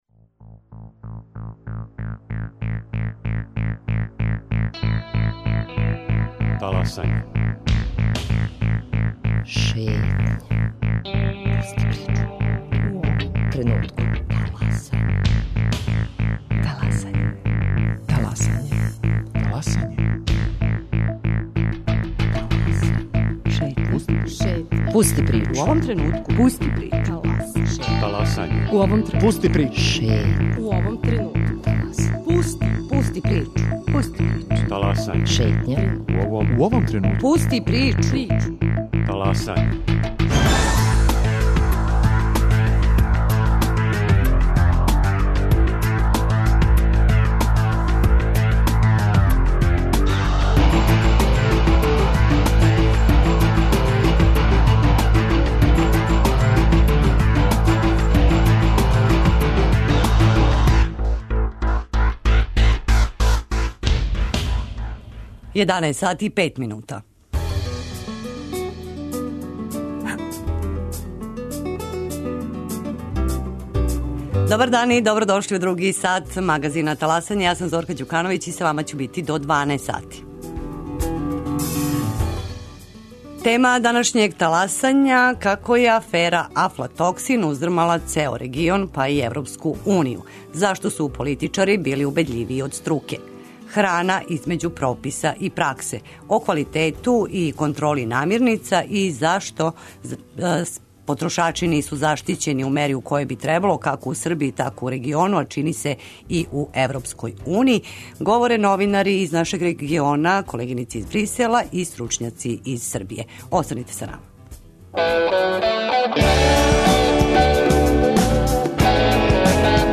О квалитету и контроли намирница и заштити потрошача и произвођача говоре новинари из региона и Брисела и стручњаци из Србије.